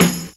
Snare (Knight).wav